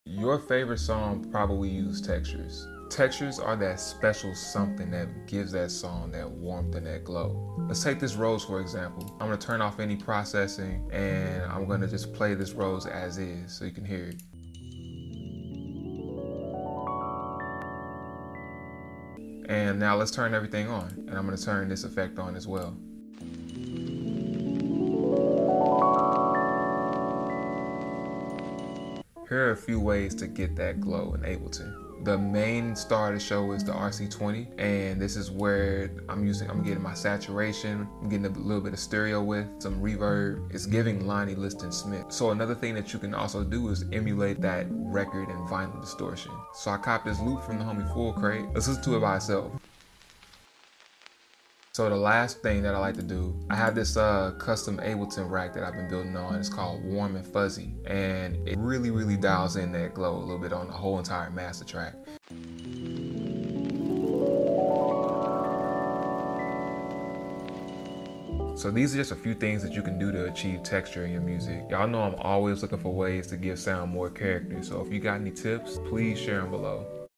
Textures add very nuanced & contrasting elements to your music that can give it that vintage warm sound. here are a few ways to do it!